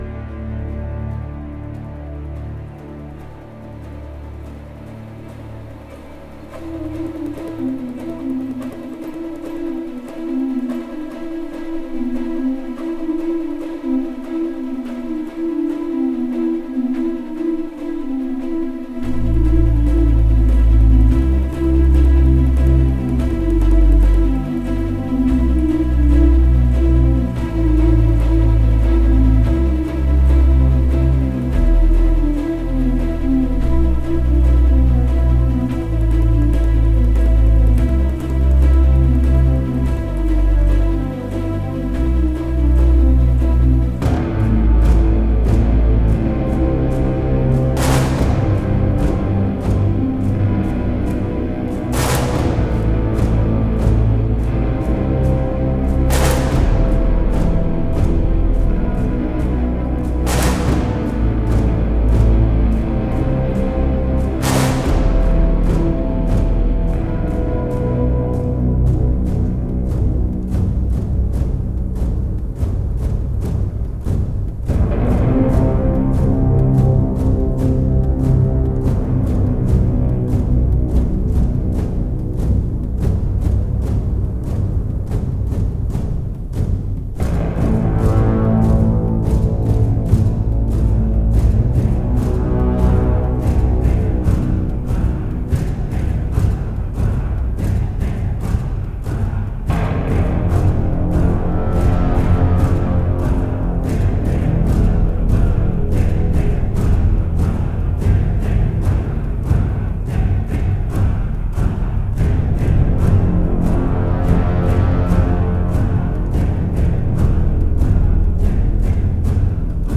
driving force